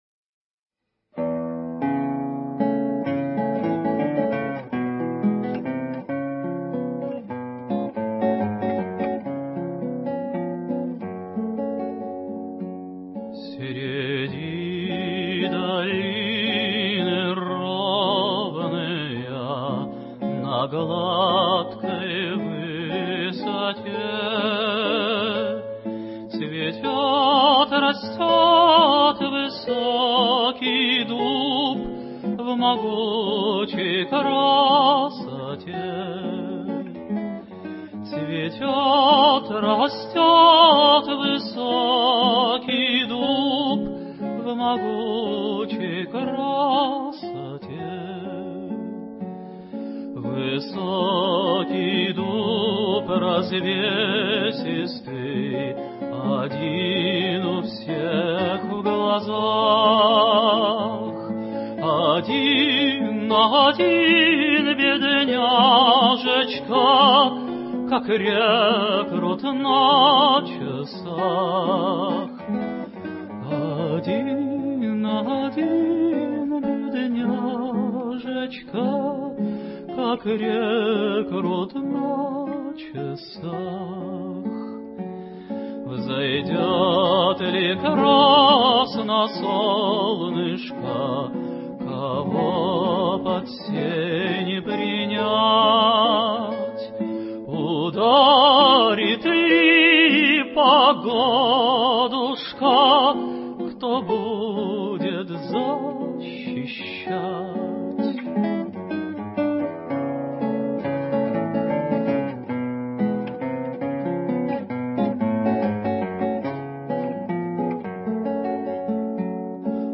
Русская народная песня «Среди долины ровныя». Исполняет Олег Погудин